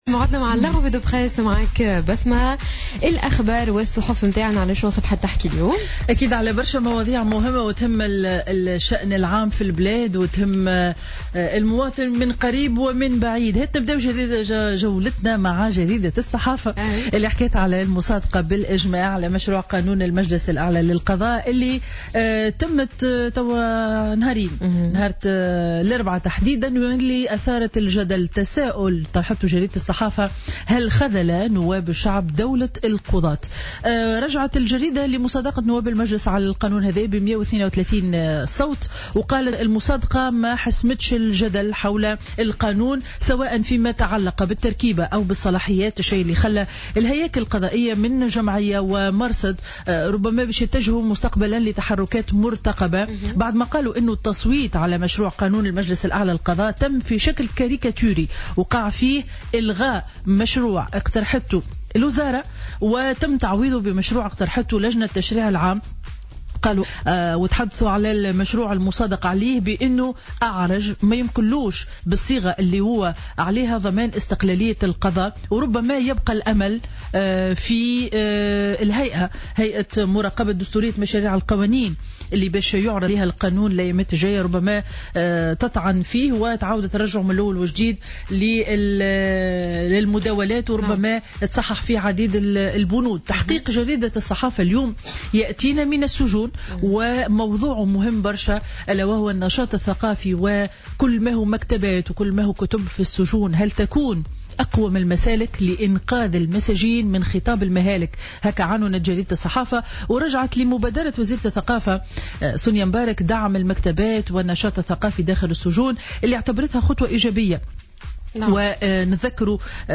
Revue de presse du jeudi 25 mars 2016